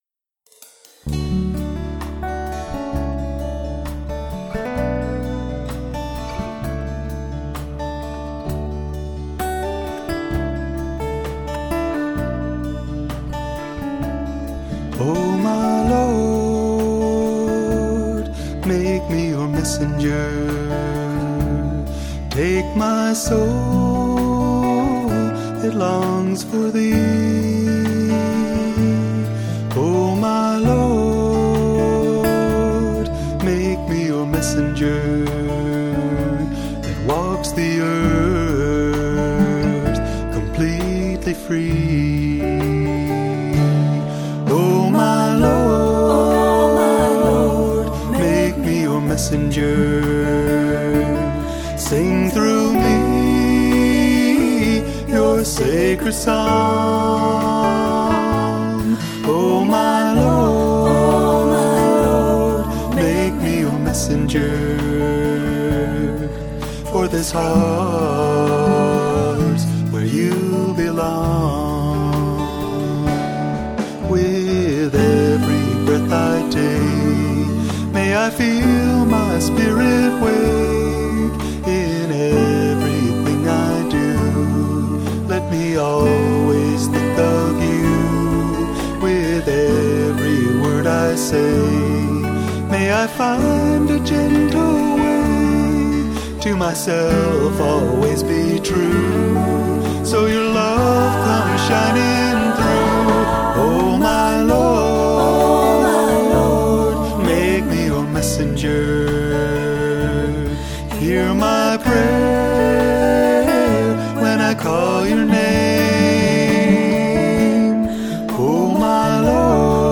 Devotional Songs
Major (Shankarabharanam / Bilawal)
8 Beat / Keherwa / Adi
Lowest Note: S / C
Highest Note: R2 / D (higher octave)